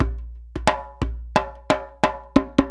089DJEMB14.wav